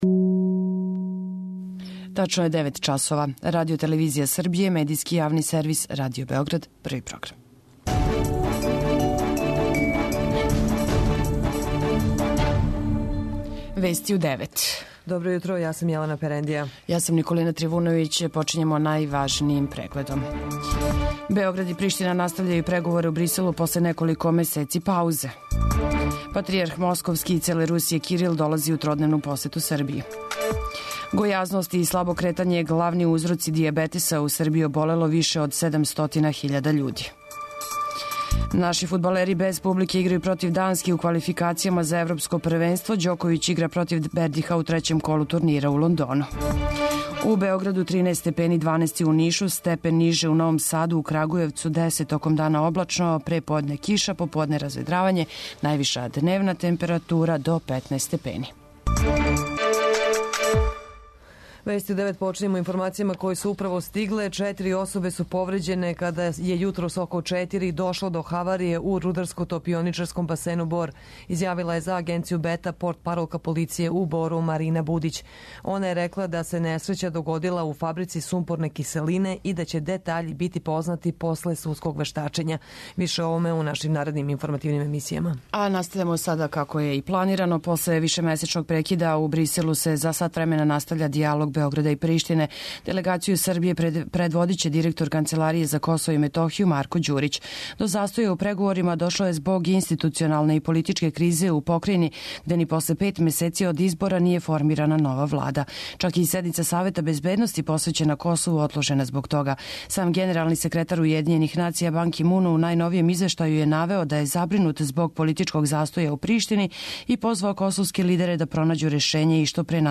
Током дана облачно, пре подне киша, поподне разведравање, температура до 15 степени. преузми : 10.43 MB Вести у 9 Autor: разни аутори Преглед најважнијиx информација из земље из света.